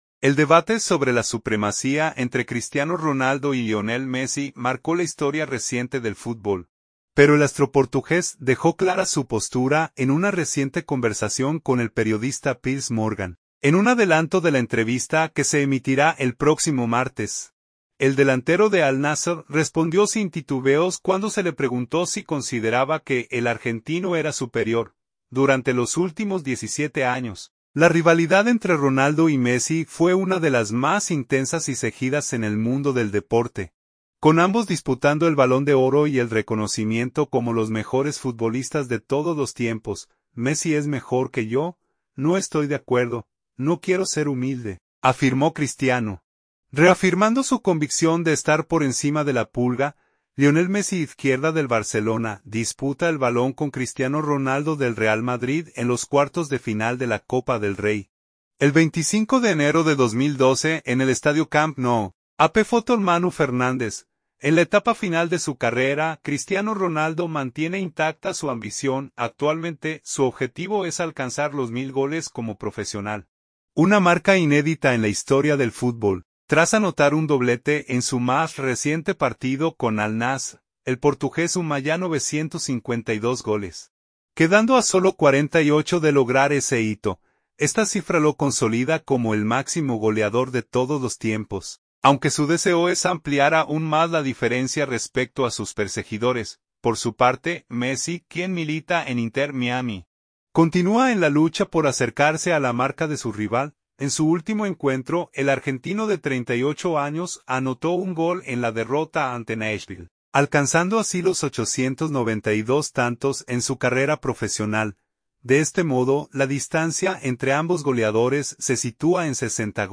En un adelanto de la entrevista que se emitirá el próximo martes, el delantero de Al Nassr respondió sin titubeos cuando se le preguntó si consideraba que el argentino era superior.